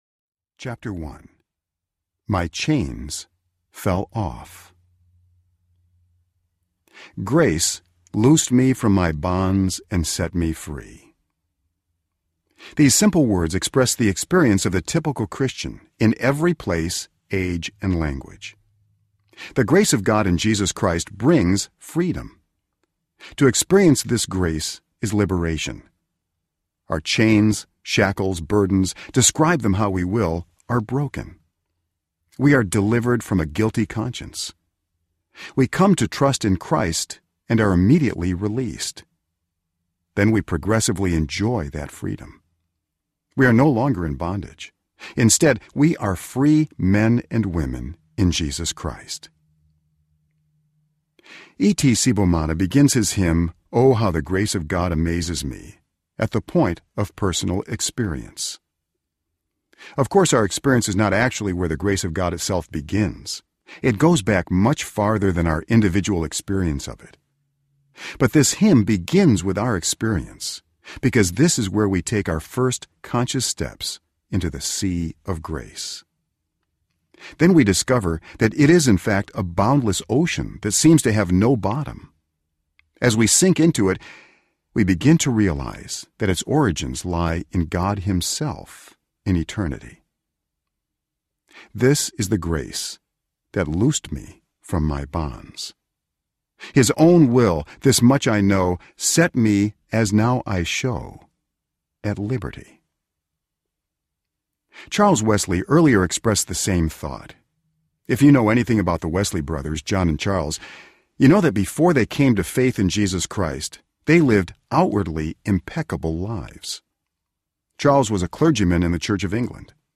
By Grace Alone Audiobook